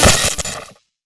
SFX item_card_lightning_hit.wav